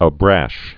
(ä-bräsh, ə-bräzh)